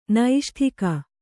♪ naiṣṭhika